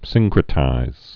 (sĭngkrĭ-tīz, sĭn-)